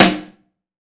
SNARE 061.wav